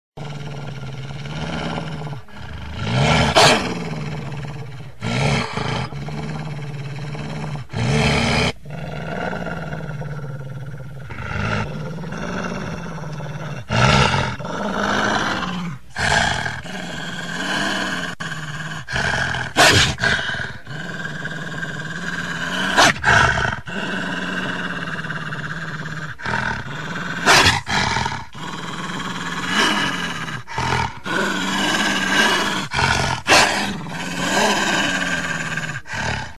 Scary Wolf Growling Téléchargement d'Effet Sonore
Scary Wolf Growling Bouton sonore